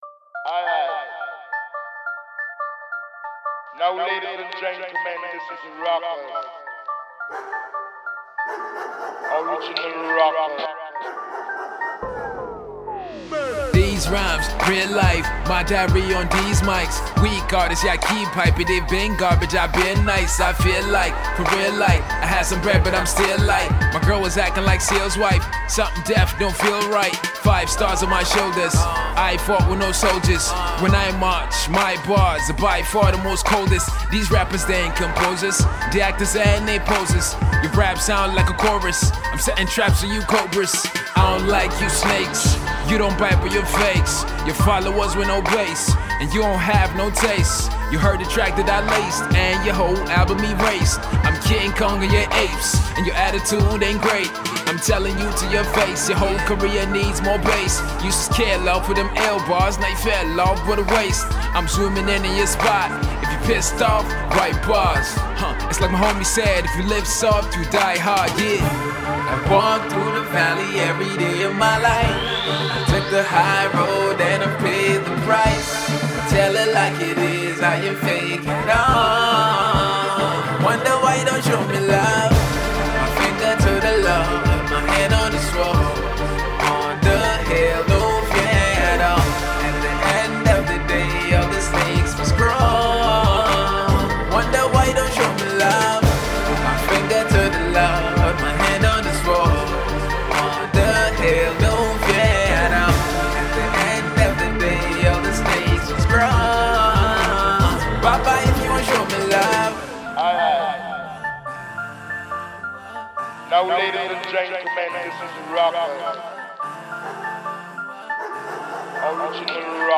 Afro Hip-Hop
Hip-Hop track